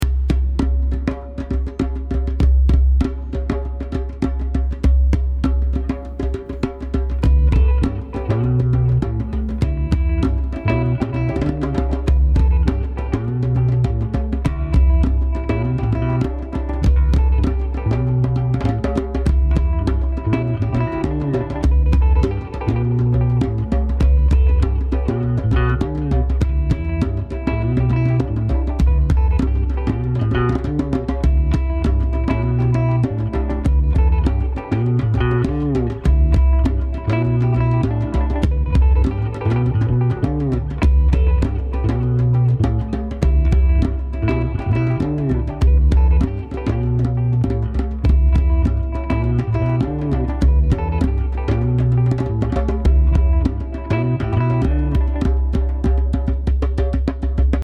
It has three sounds, essentially, (but you can be creative there). Bass, tone, and slap.
I’ve gone to a good condenser on the top mic. 2 tracks, simultaneous.
Reverb and all that is situational, but nearly always 3 tracks.
Kick in the middle, spread out the top.
My djembe drum
djembe-100.mp3